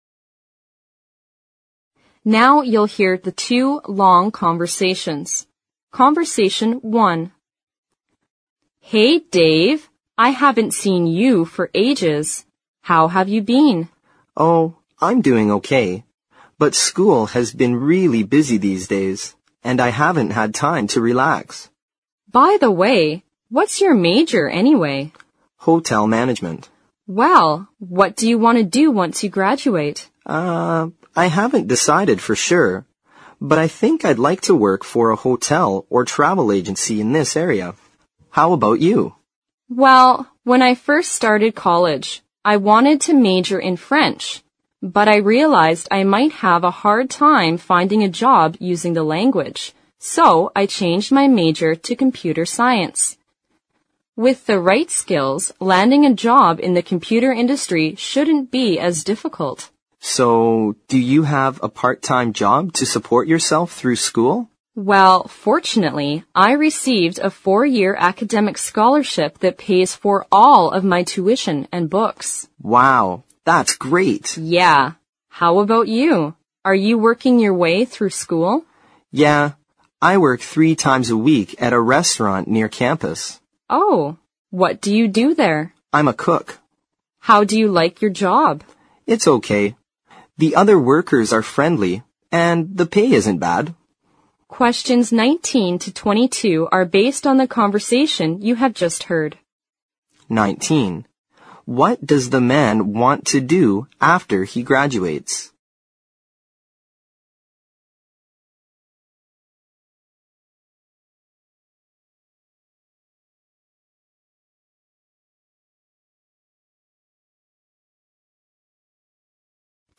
Now you'll hear the two long conversations.